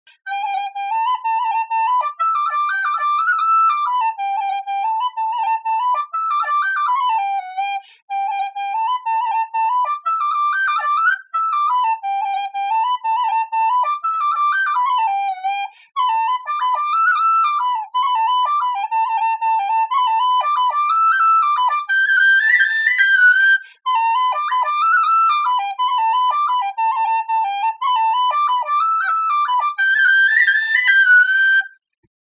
Whistle
I think rolls sound wonderful for the Kesh jig, particularly those repeated G and A notes in the beginning of the A part.
kesh_jig.mp3